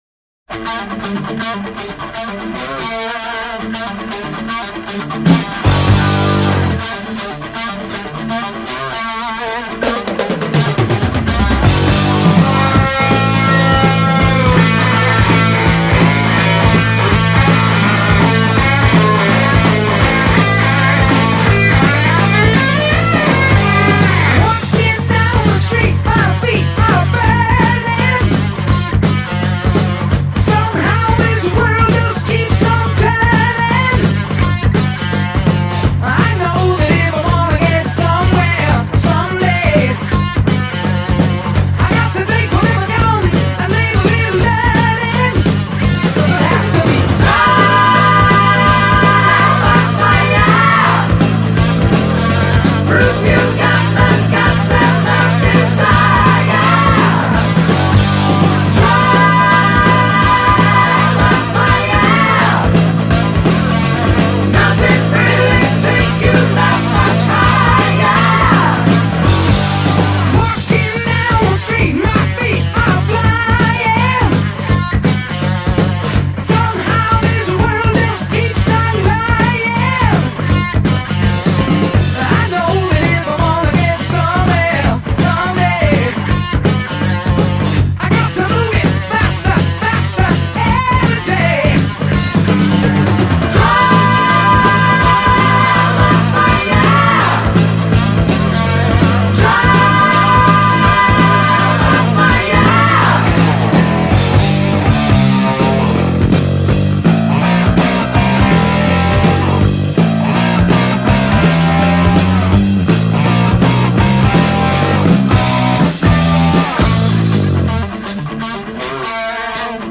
guitarist
his fiery riffs and leads